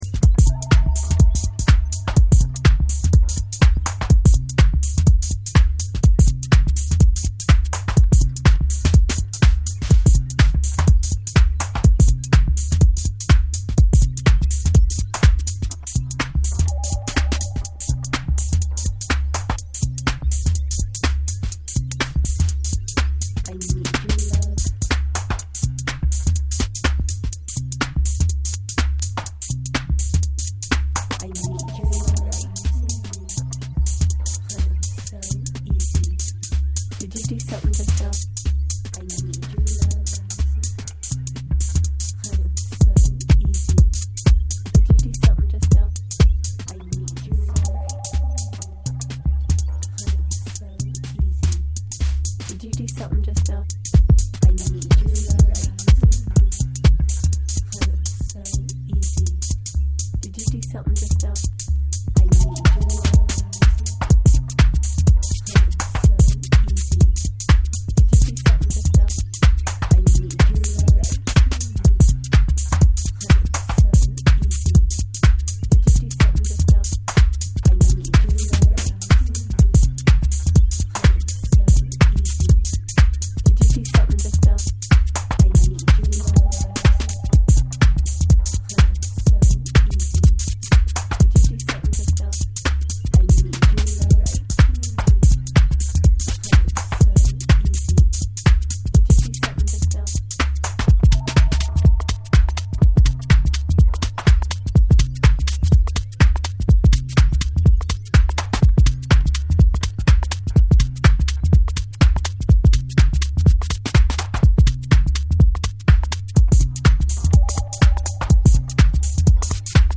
The EP includes two trippy and straight minimal tracks.